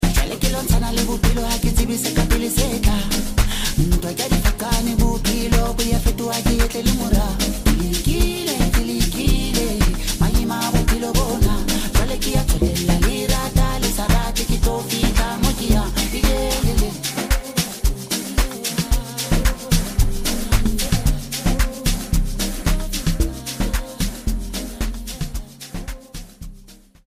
bro carrying sotho amapiano